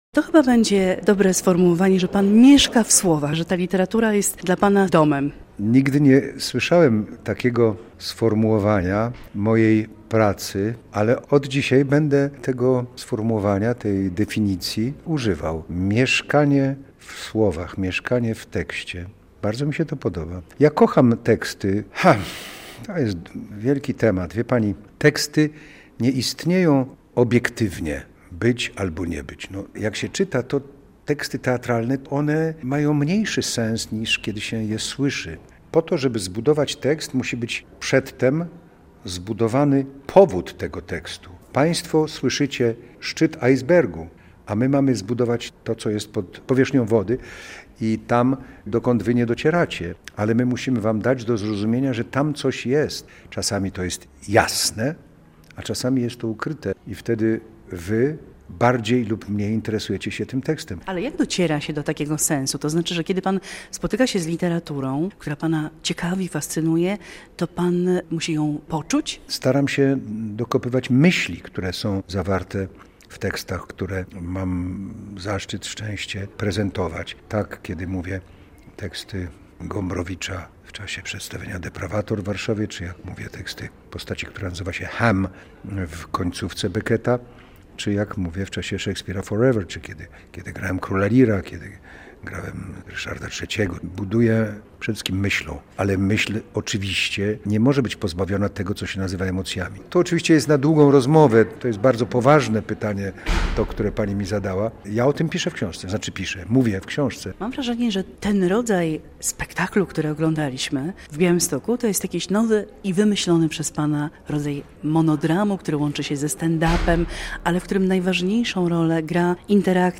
Kiedy rozmawia, sprawia wrażenie, jakby też tworzył spektakl.